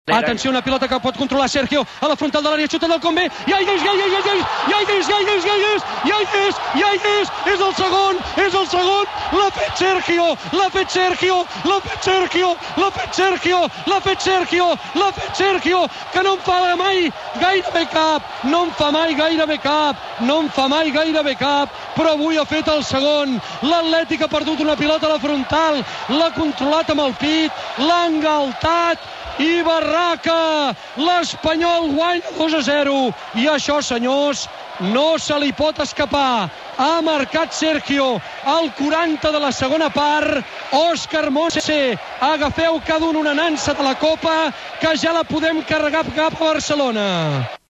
Narración del gol de Sergio en Catalunya Radio